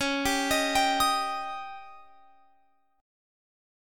Listen to C#sus2b5 strummed